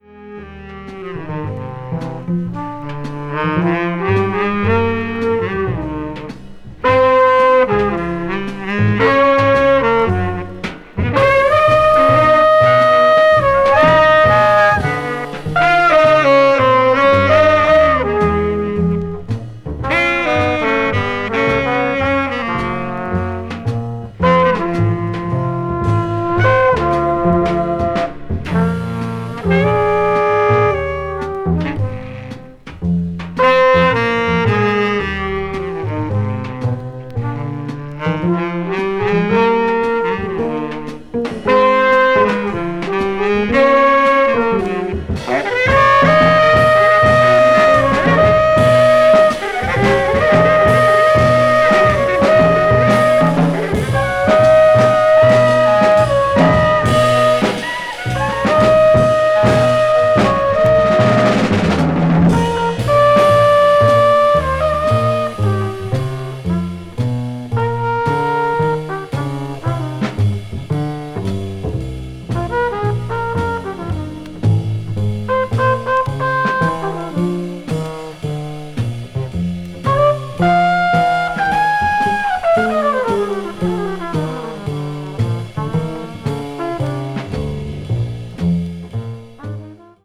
avant-jazz   free jazz   post bop